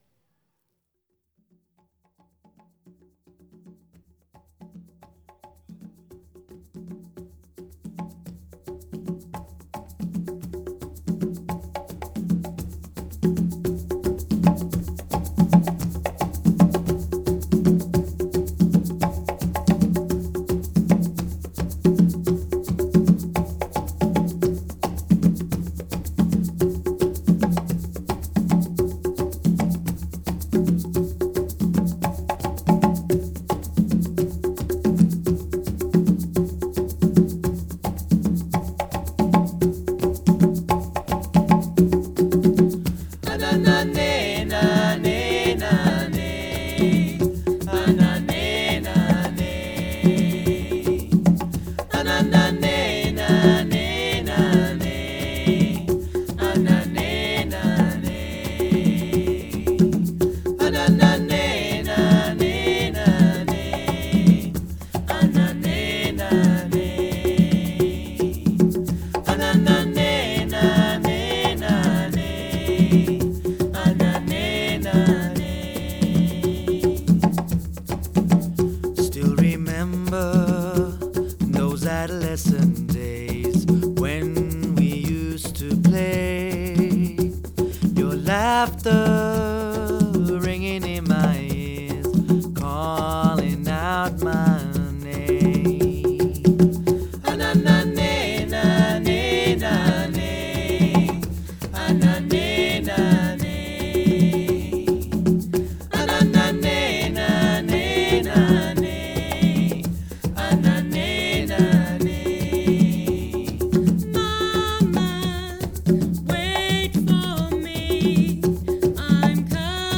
percussion bands